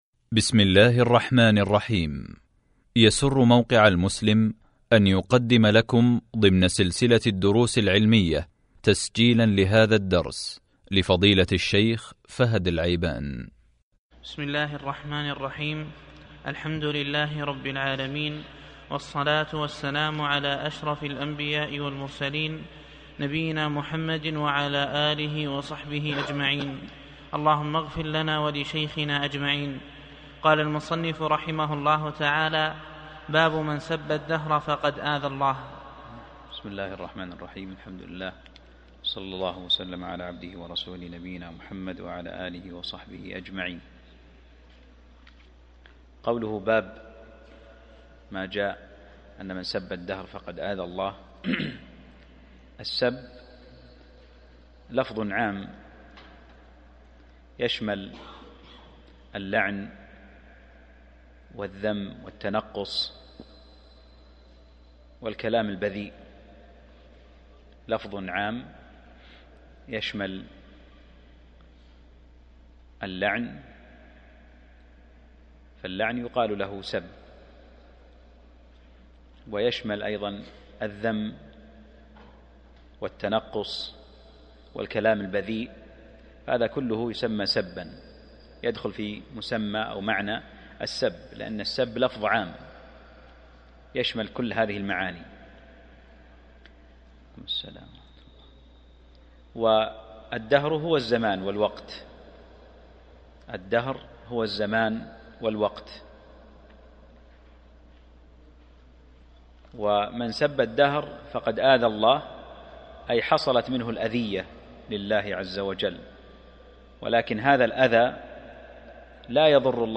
الدرس (42) من شرح كتاب التوحيد | موقع المسلم